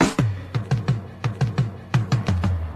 Chopped Fill 10.wav